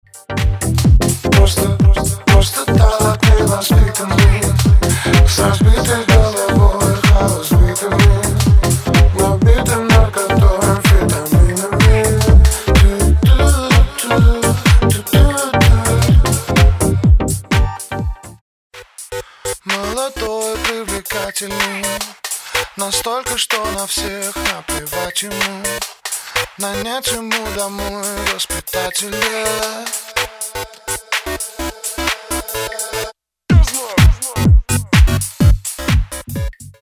В песне, которая в примере, есть обработанный и необработанный вокал (такая аранжировка).